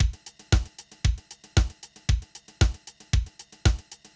MOO Beat - Mix 5.wav